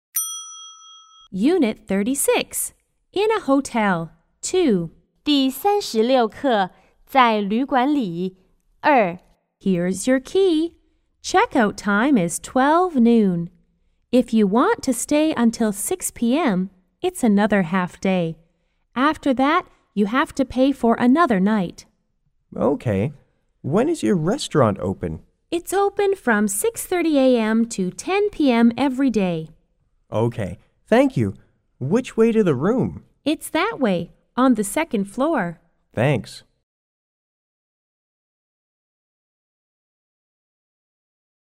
R= Receptionist T= Traveler